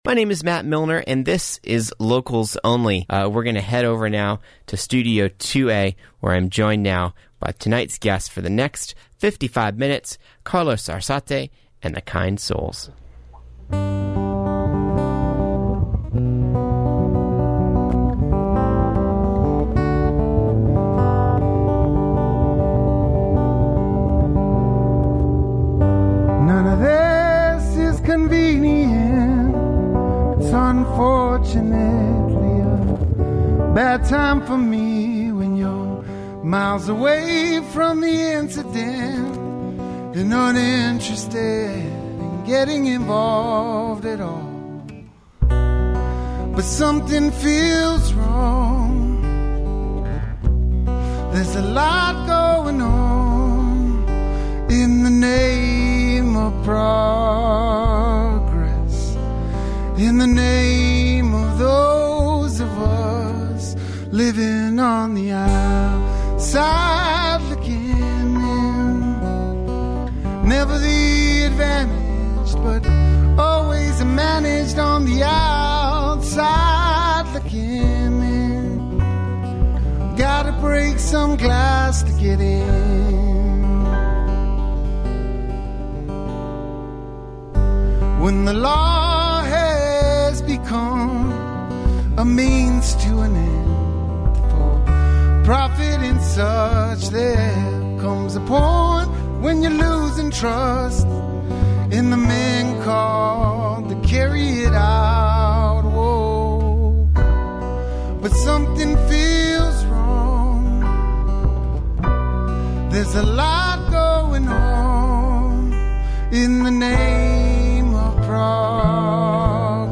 singer, songwriter
folk, rock, soul & R&B